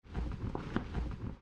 catch_air_5.ogg